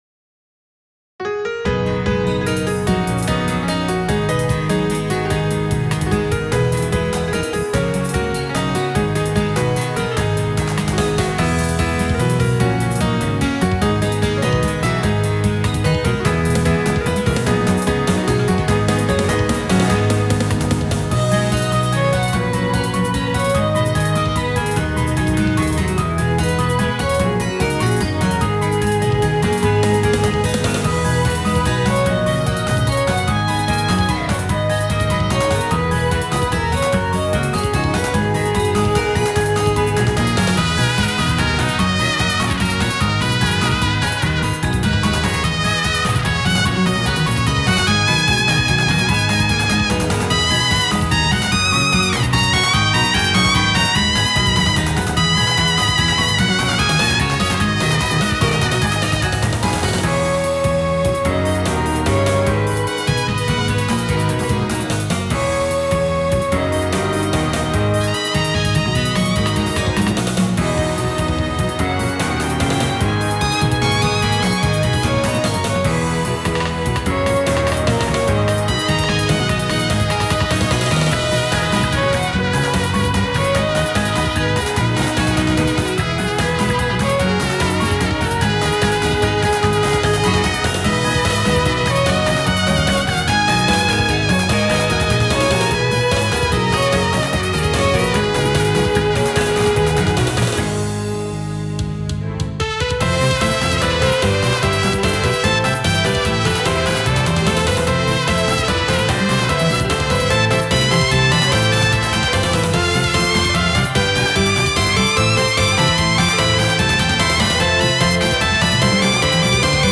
[Piano; guitarra acústica]
Batería; cuerdas; bajo
Violín; trompeta